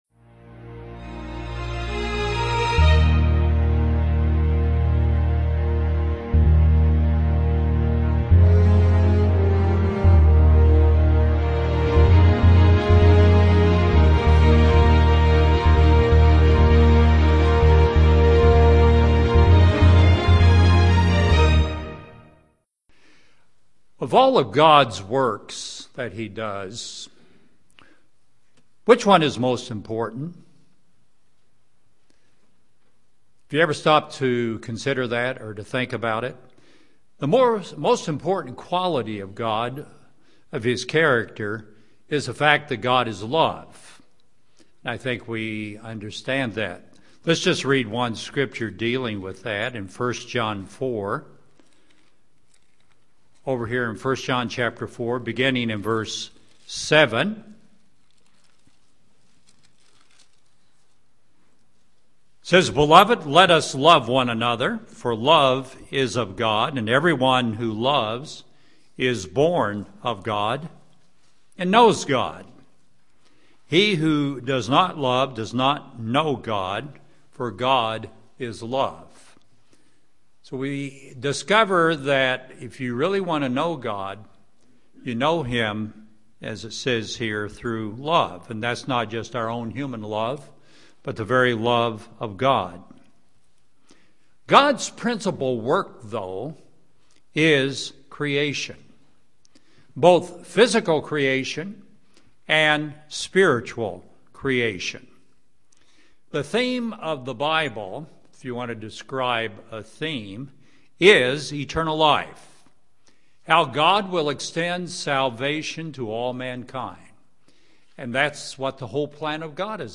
How God lives in us UCG Sermon Transcript This transcript was generated by AI and may contain errors.